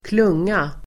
Uttal: [²kl'ung:a]